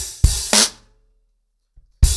BlackMail-110BPM.7.wav